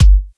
Kick Fancy 1.wav